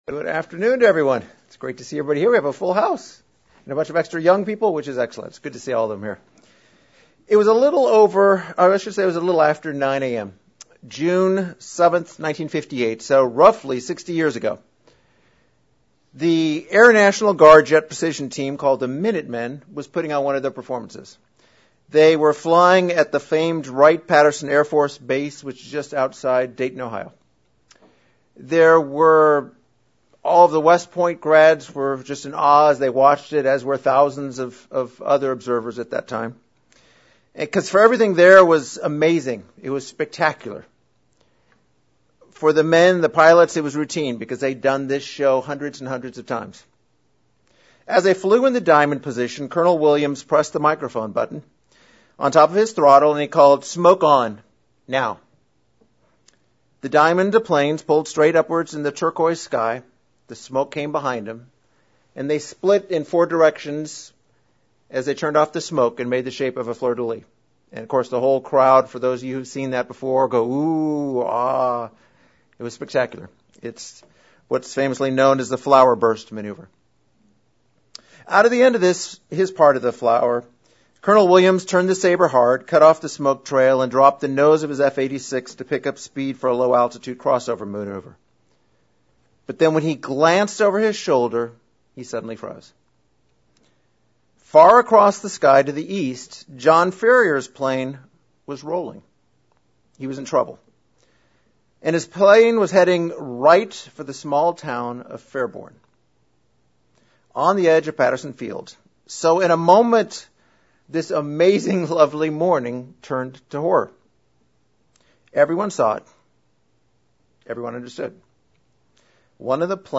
Split sermon